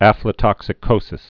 (ăflə-tŏksĭ-kōsĭs)